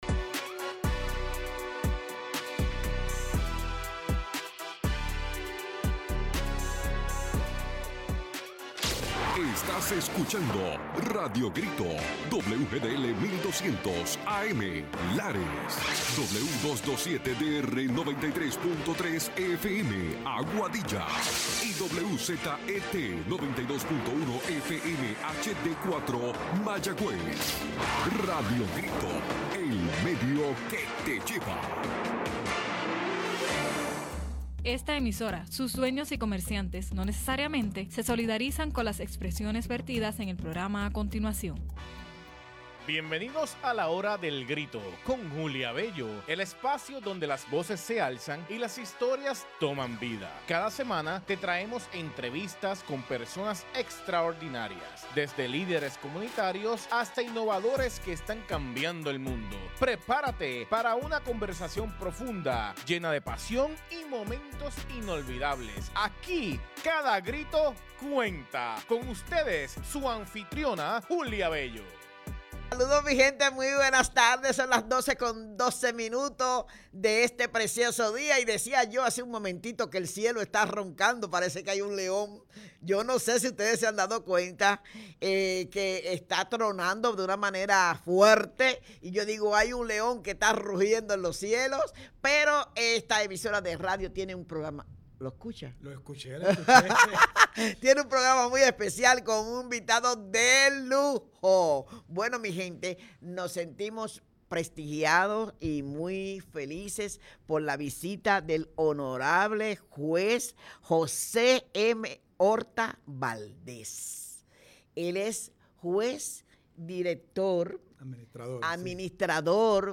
Nos visita el Hon. José Orta Valdéz, Juez Administrador de la Región Judicial de Utuado, para una conversación sobre justicia, comunidad y servicio público.